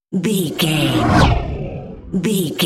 Horror whoosh creature
Sound Effects
Atonal
scary
ominous
eerie
whoosh